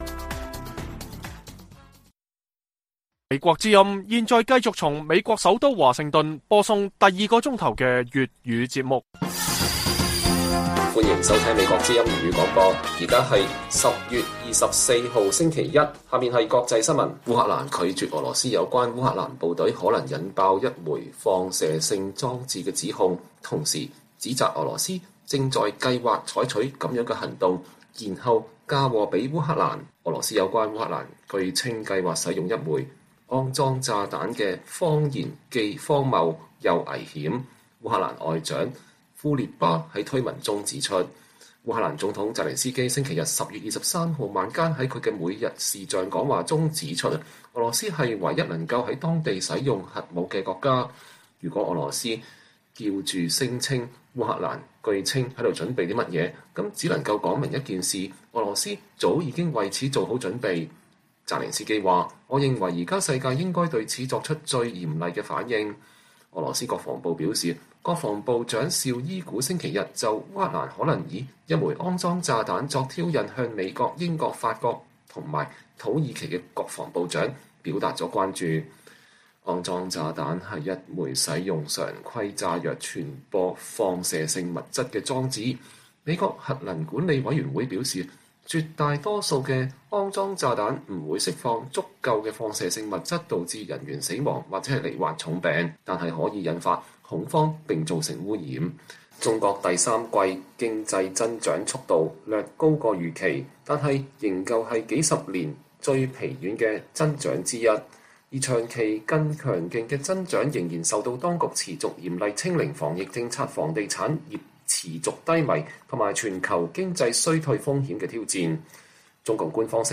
粵語新聞 晚上10-11點: 烏克蘭及其西方盟友拒絕俄羅斯“髒彈”指控